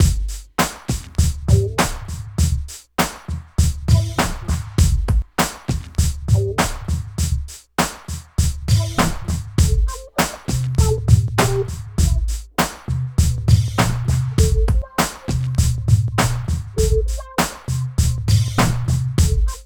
88 LOOP   -R.wav